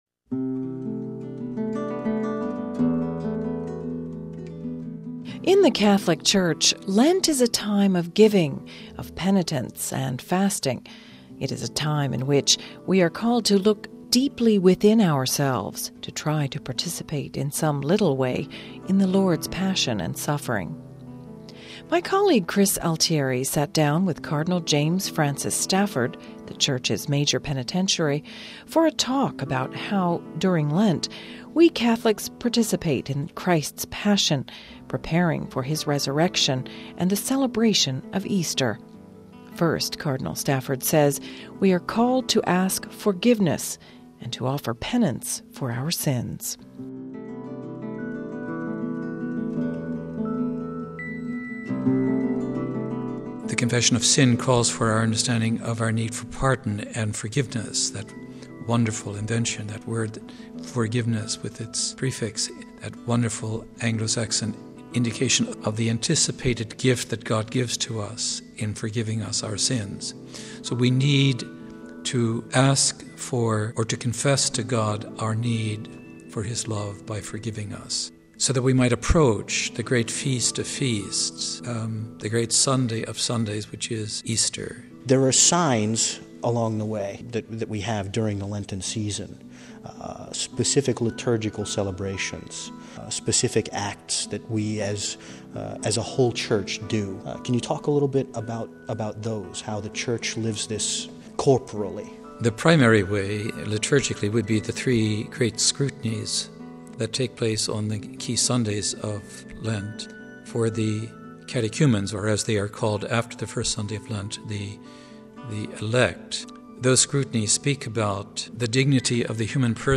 Home Archivio 2007-03-09 13:10:21 PREPARING FOR EASTER We talk with the Church’s Major Penitentiary, Cardinal James Stafford about spiritual preparations for the Easter Feast...